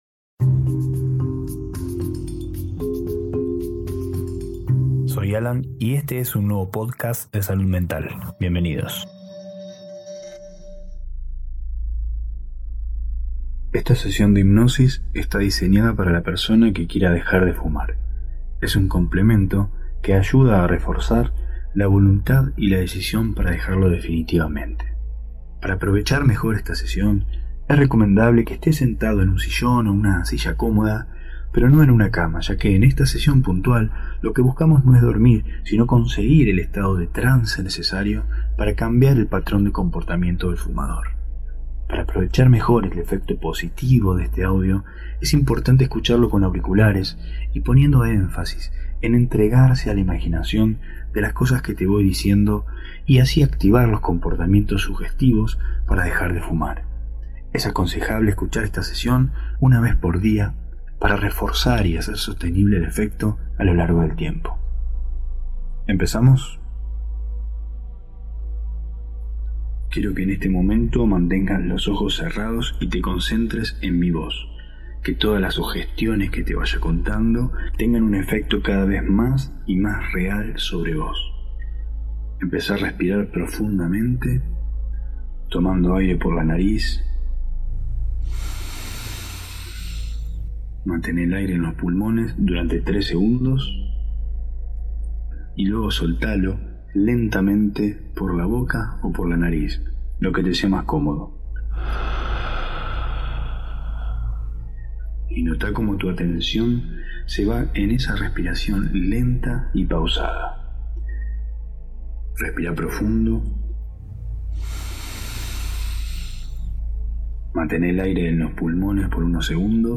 Sesión de autohipnosis para reforzar el proceso de discontinuar el tabaquismo.